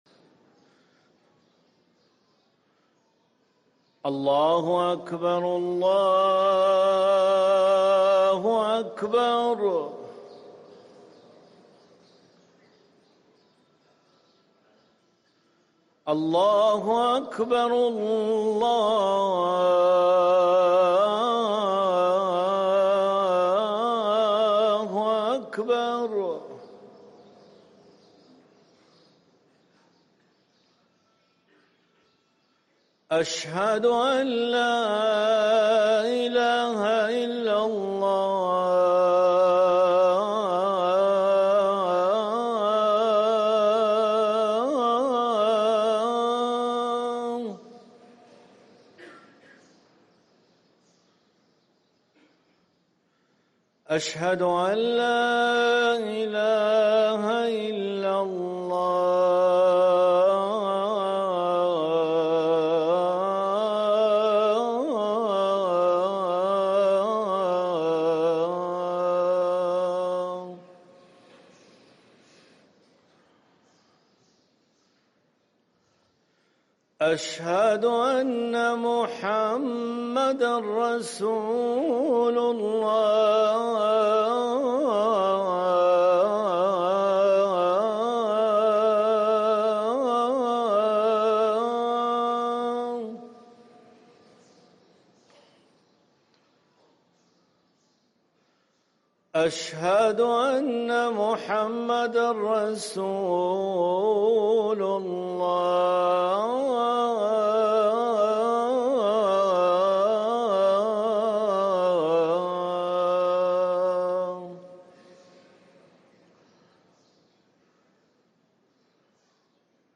اذان الفجر